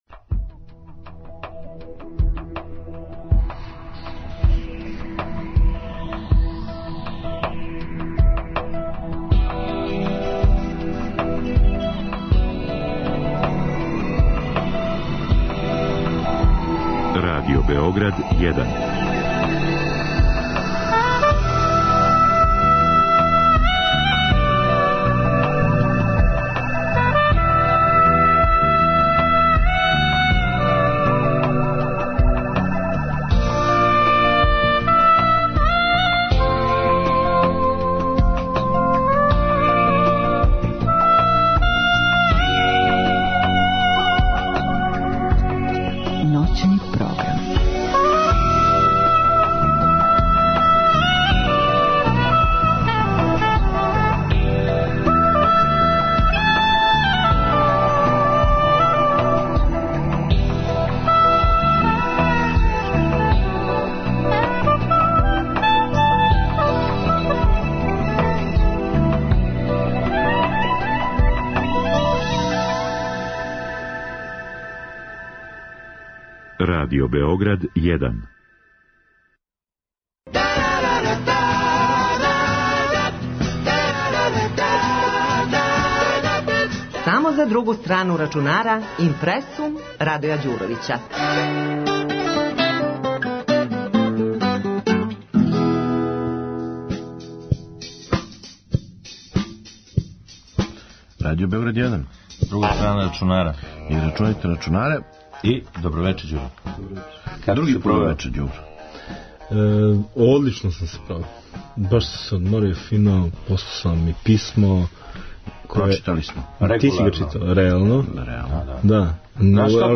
Ovo je bila tema stalne rubrike IMPRE§UM emisije "Druga strana računara" noćnog programa Radio Beograda 1, emitovanog 26. Aprila 2014.godine. Audio zapis IMPRE§UM-a možete čuti na kraju ovog teksta.